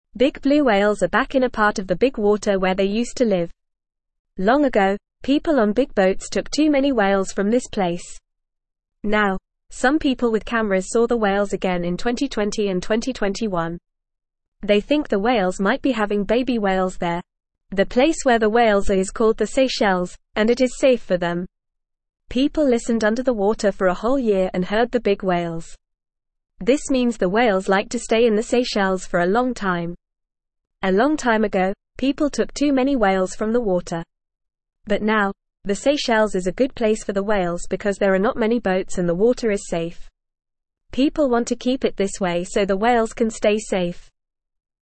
Fast
English-Newsroom-Beginner-FAST-Reading-Blue-Whales-Return-to-the-Seychelles-a-Safe-Home.mp3